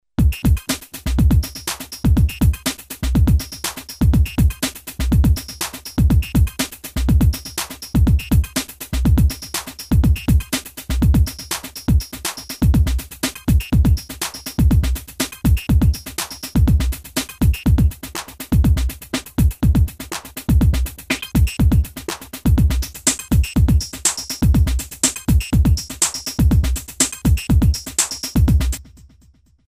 Mostly DJ-Live oriented in the LOOP FACTORY series grooveboxes based on AN analog physical modeling synthesis and sampled percussions.
Drum PCM
demo distortion